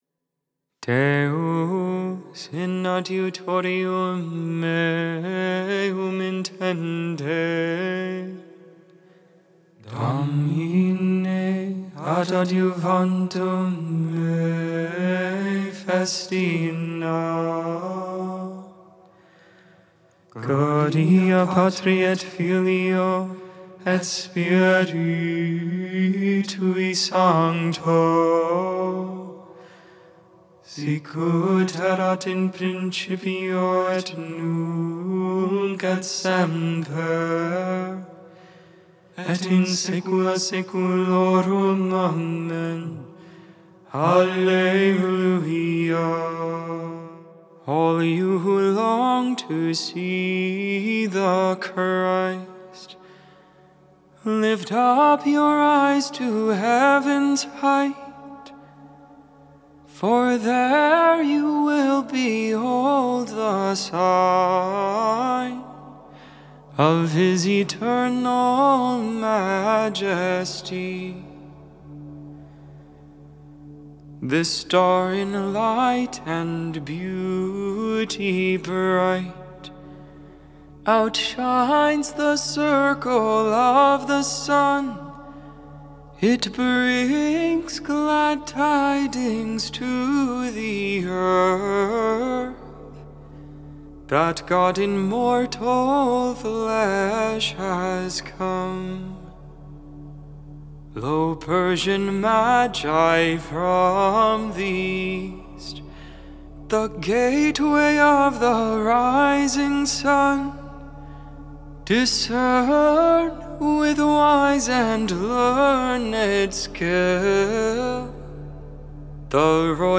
1.7.24 Lauds, Sunday Morning Prayer